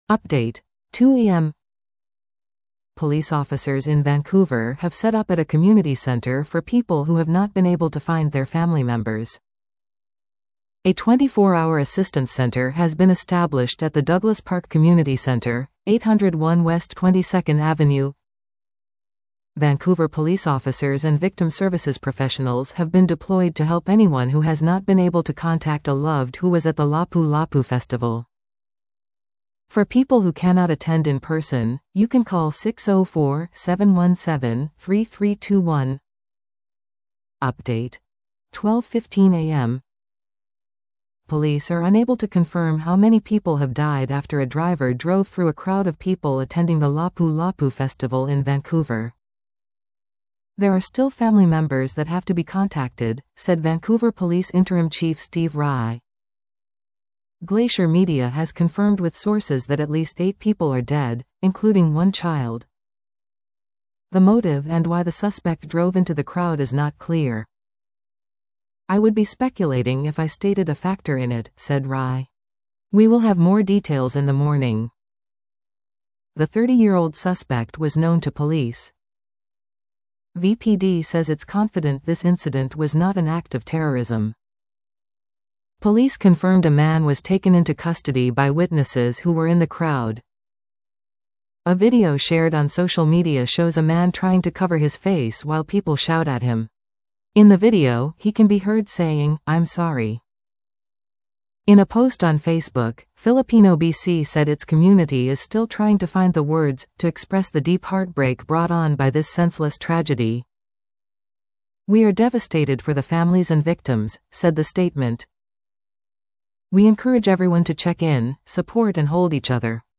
Vancouver Police Department (VPD), BC Premier David Eby speak after deadly car attack at Vancouver Lapu Lapu Day block party; "darkest day in our city."